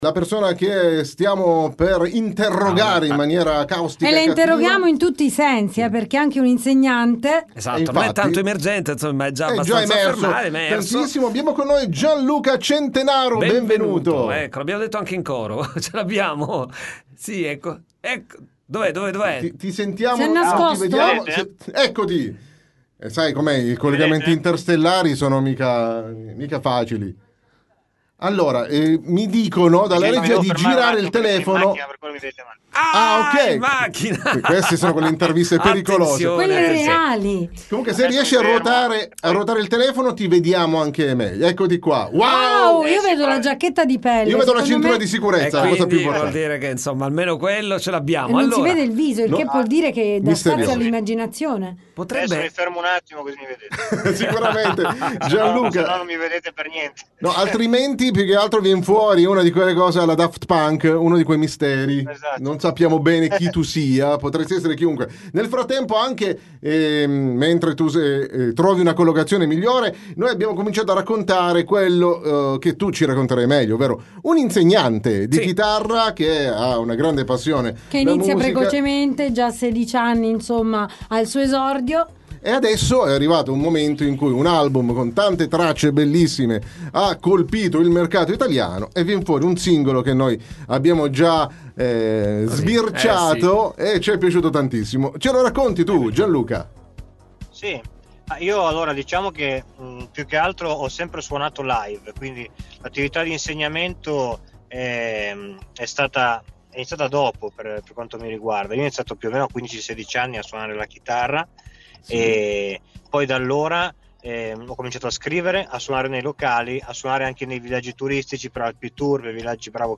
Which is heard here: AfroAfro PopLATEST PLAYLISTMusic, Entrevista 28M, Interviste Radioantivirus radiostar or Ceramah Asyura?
Interviste Radioantivirus radiostar